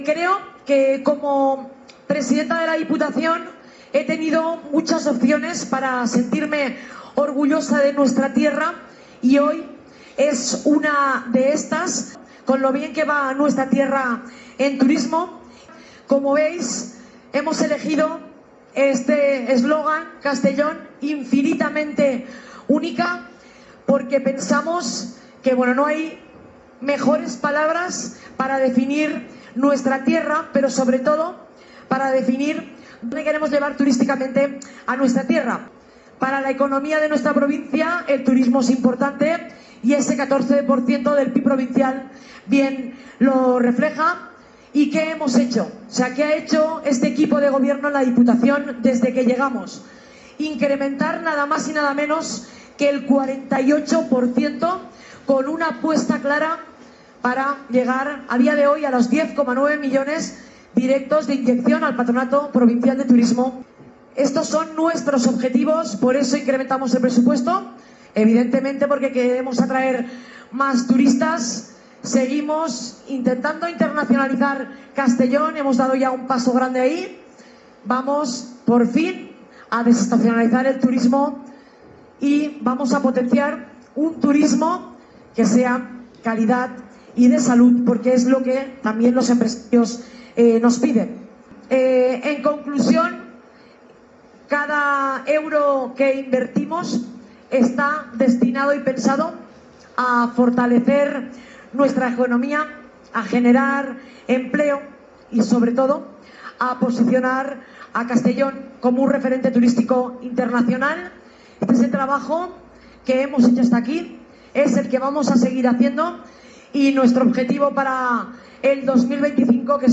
La presidenta de la institución provincial, Marta Barrachina, ha presentado este miércoles en la Feria Internacional de Turismo de Madrid (Fitur) la Estrategia Turística Provincial que persigue los objetivos de atraer más turistas, internacionalizar Castellón, desestacionalizar el turismo y potenciar un turismo de calidad y salud.
Presidenta-de-la-Diputacion-Marta-Barrachina-Presentacion-Estrategia-Turistica-Provincial.-Fitur.mp3